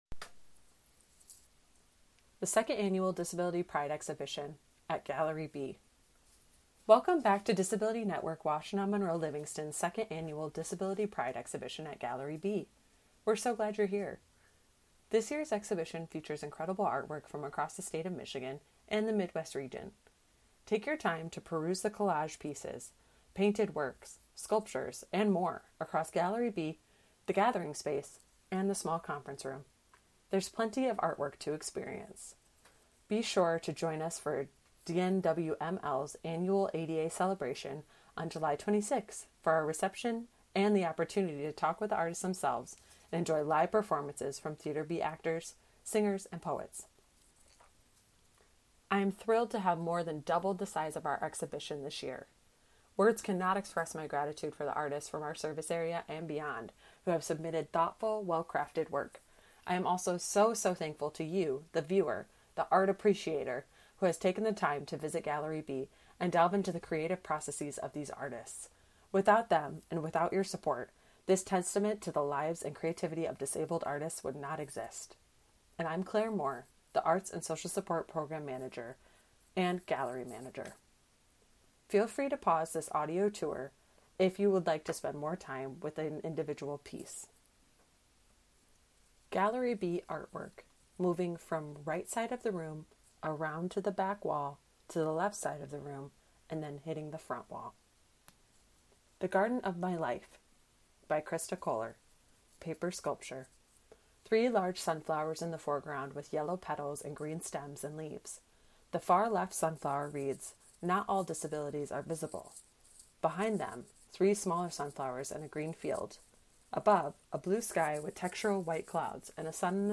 Feel free to pause the audio tour at any time to allow yourself more time with each piece.